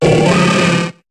Cri de Kicklee dans Pokémon HOME.